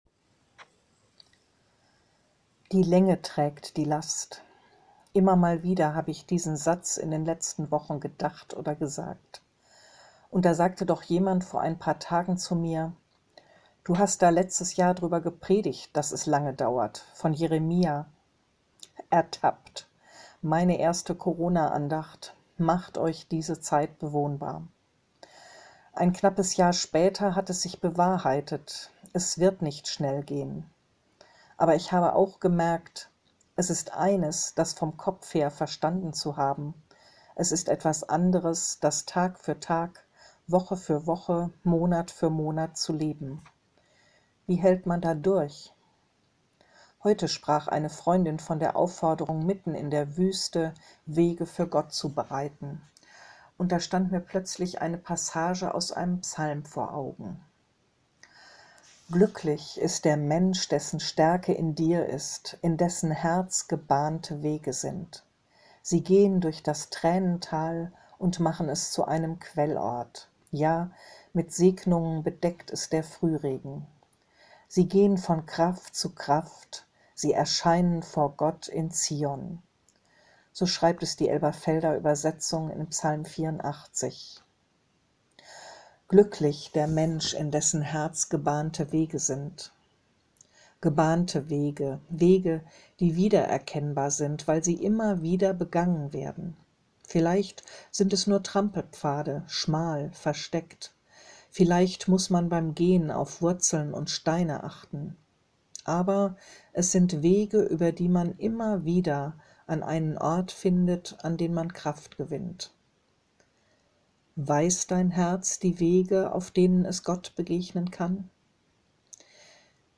Andacht